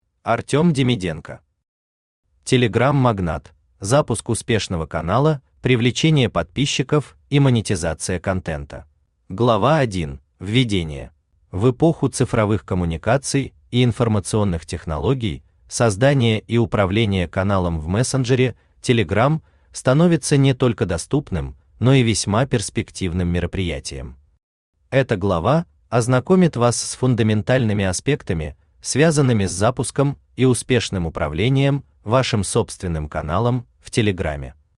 Aудиокнига Telegram-магнат: Запуск успешного канала, привлечение подписчиков и монетизация контента Автор Артем Демиденко Читает аудиокнигу Авточтец ЛитРес.